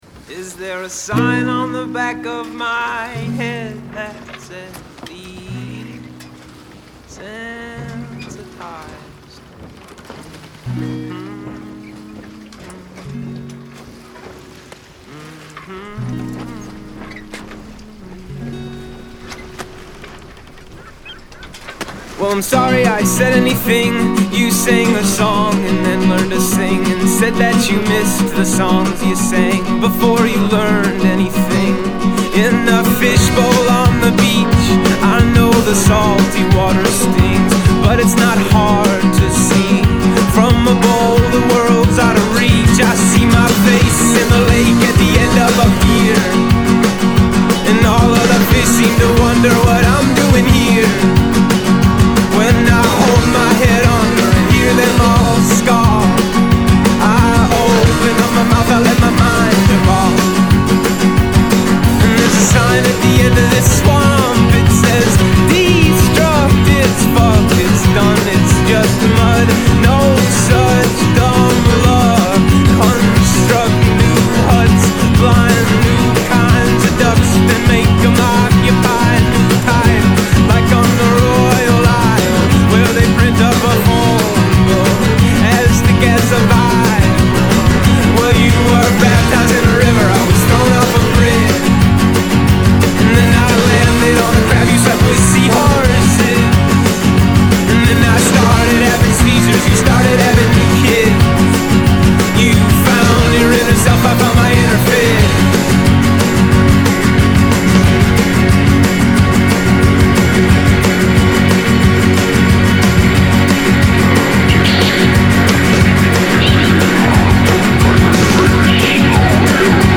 psych-folk band
fusing meticulously worded songs and spaced-out jams
Live the songs are dynamic and energetic